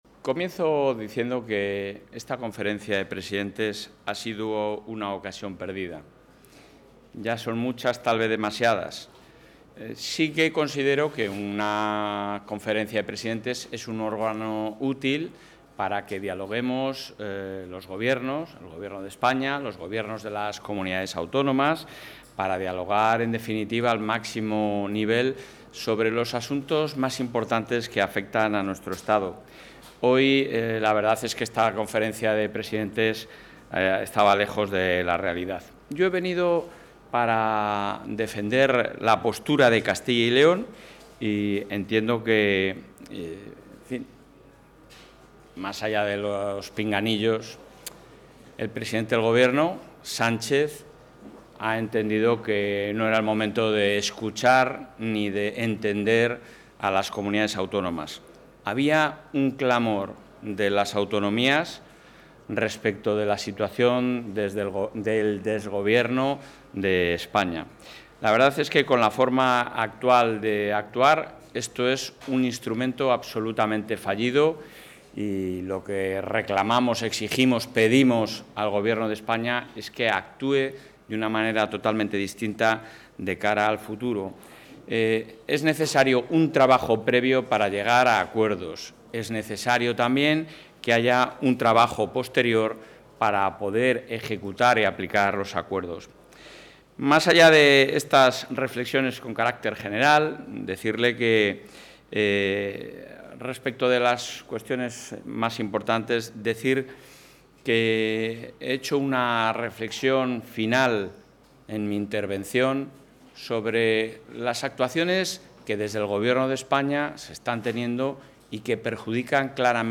Intervención del presidente de la Junta.
El presidente de la Junta de Castilla y León, Alfonso Fernández Mañueco, ha participado hoy en la Conferencia de Presidentes celebrada en Barcelona, donde ha urgido al Gobierno de España la necesidad de abordar diferentes asuntos de interés para las personas de la Comunidad y el conjunto de España, desde un compromiso con la defensa de los intereses de Castilla y León, la lealtad institucional, el respeto al Estado de Derecho y la responsabilidad con los ciudadanos.